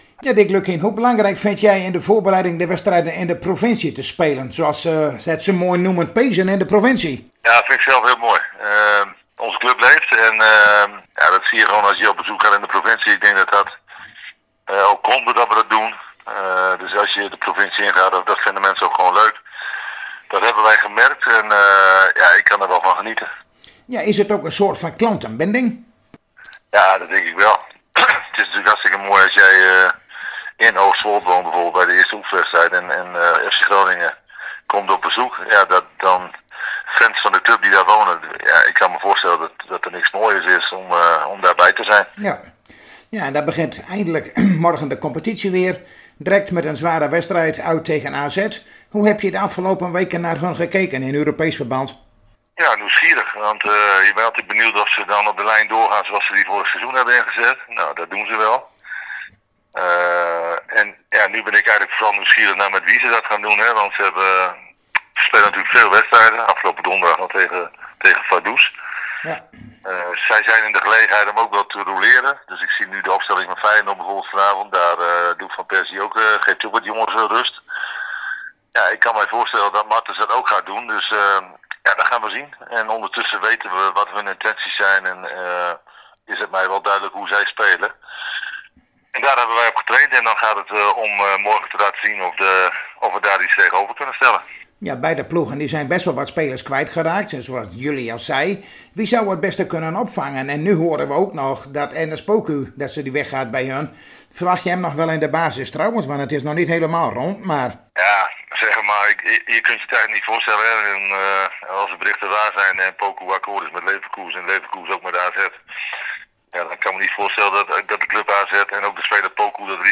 Zojuist spraken wij weer met hoofdtrainer Dick Lukkien van FC Groningen over de voorbereiding van de FC in aanloop naar het komende seizoen, over de wedstrijd van morgen tegen AZ en ook vroegen wij zijn meningen ovder de jonge talenten die hij in de voorbereiding mee heeft laten doen.